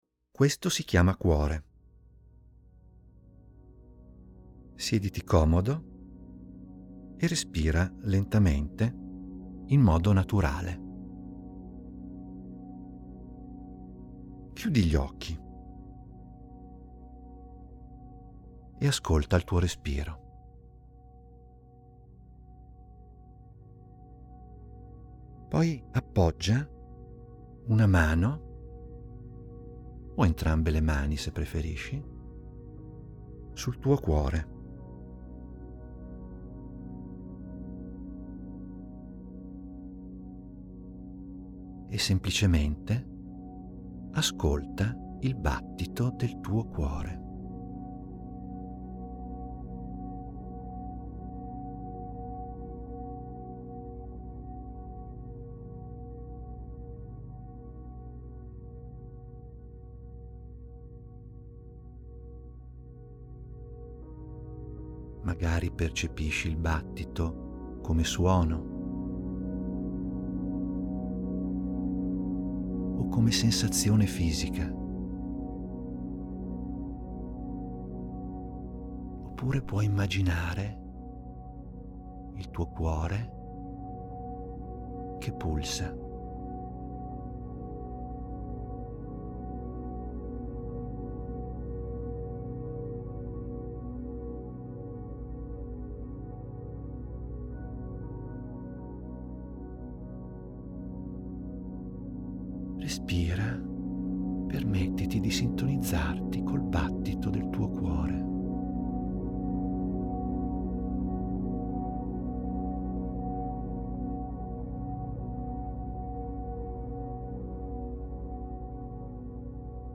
In questa sezione trovi degli audio che ti guidano a svolgere delle mini-meditazioni di due minuti.